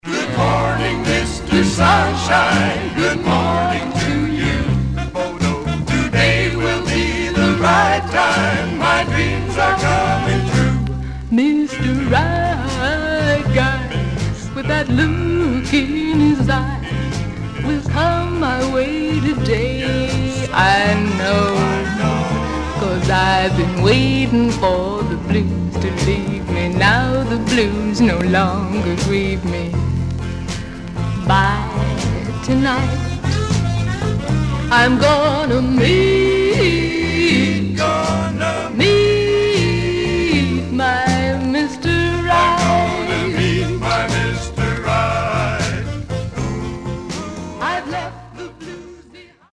Some surface noise/wear Stereo/mono Mono
Teen